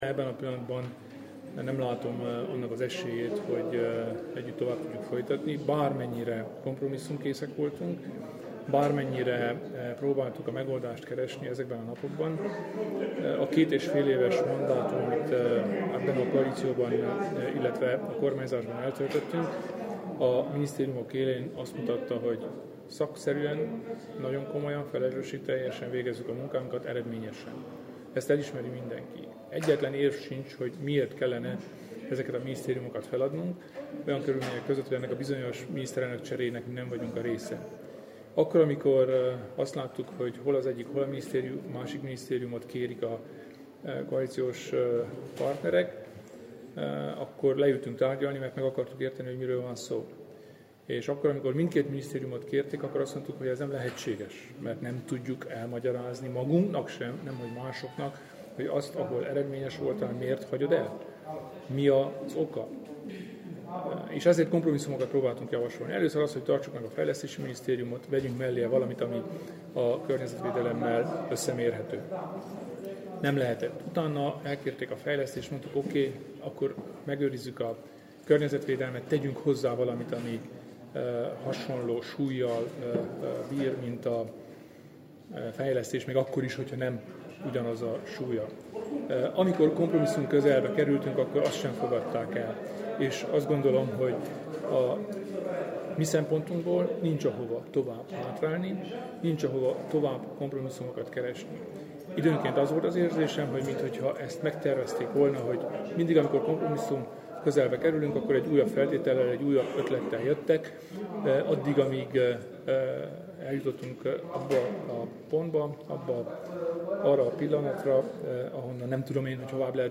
Nekünk nincs hova tovább hátrálni, amikor meg akarnak fosztani az általunk vezetett összes minisztériumtól – Kelemen Hunor nyilatkozata – Új Hét
Kelemen Hunor szövetségi elnök kedd este kijelentette, hogy ha a koalíciós partnerek nem változtatnak az álláspontjukon, az RMDSZ nem vesz részt az új kormányban. A parlamentben nyilatkozó politikus elmondta, hogy a keddi tárgyalásokon nem sikerült egyezségre jutni a másik két koalíciós partnerrel az új kormány összetételéről.